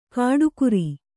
♪ kāḍu kuri